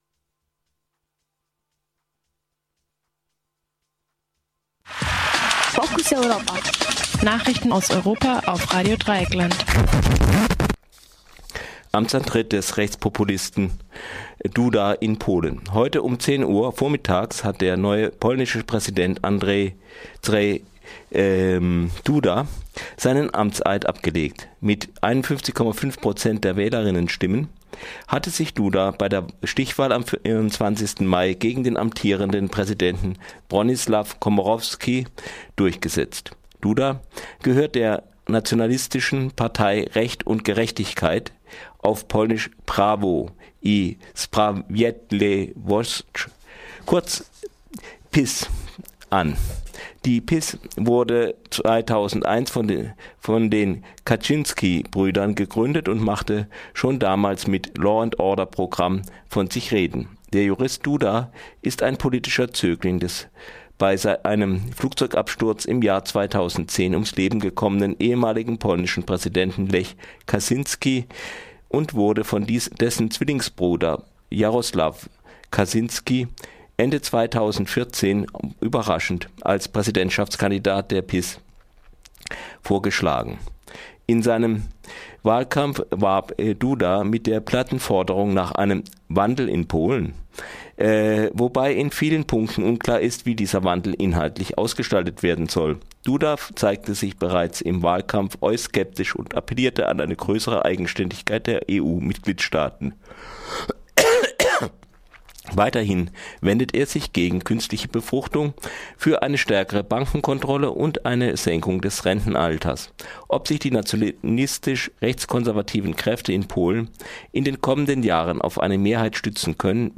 Focus-Europa Nachrichten, 6.8.2015